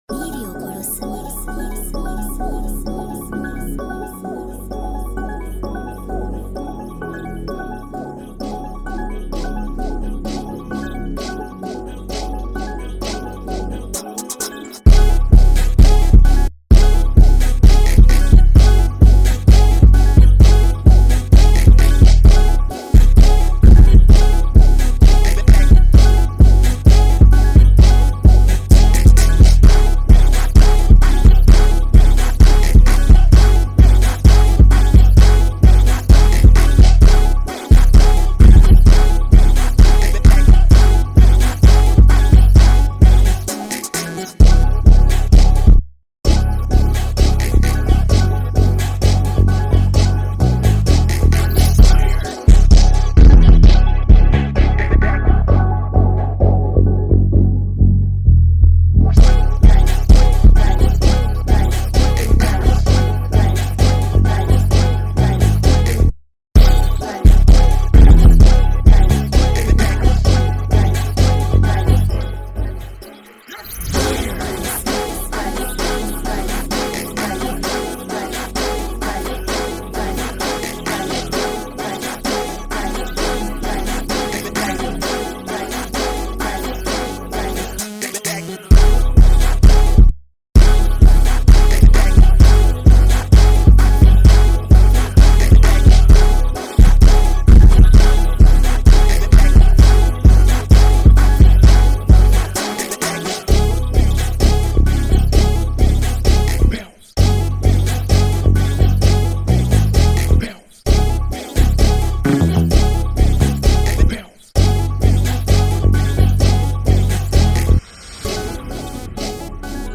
official instrumental
2024 in New Jersey Club Instrumentals